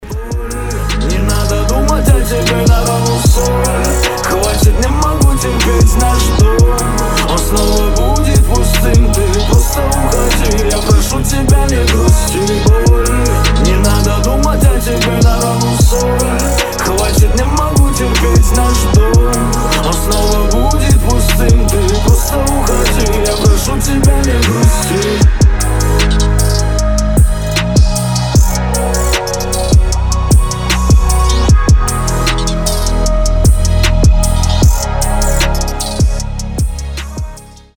лирика
грустные
атмосферные
русский рэп
басы